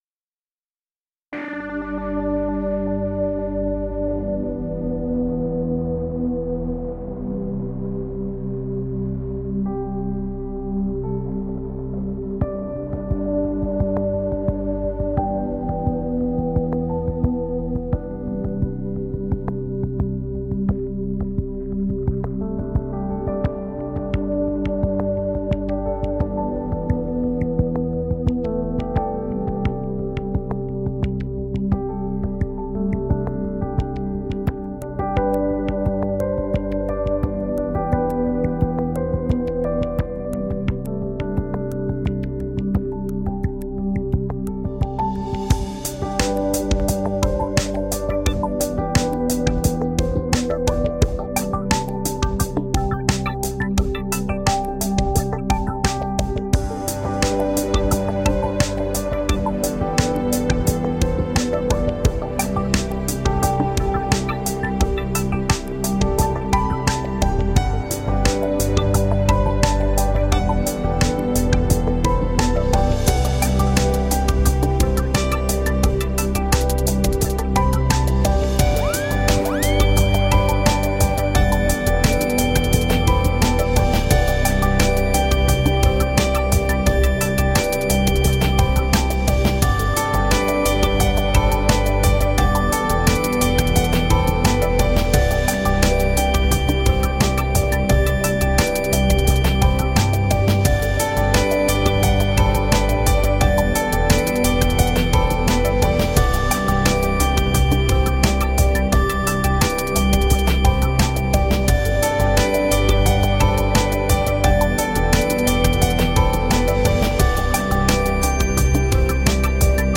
Uplifting electronica and melodic soundscapes.
Recorded on the island of Martha's Vineyard, MA USA